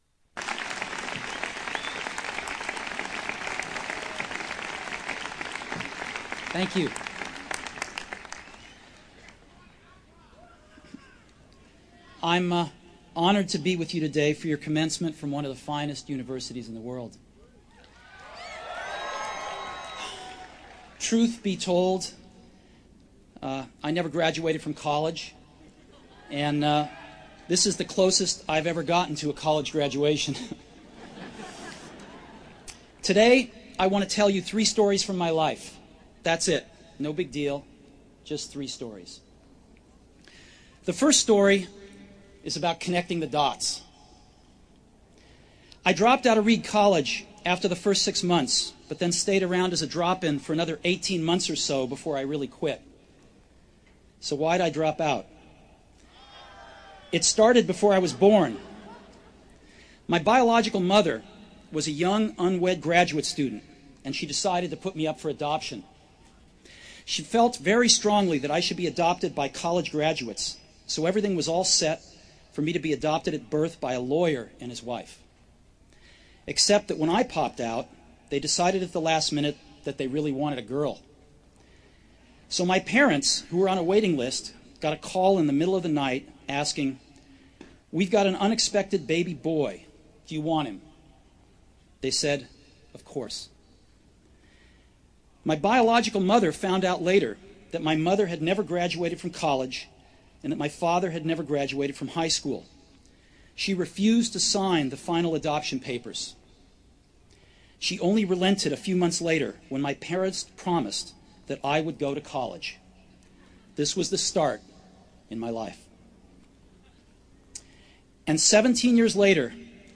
SteveJobsSpeech_mono.wav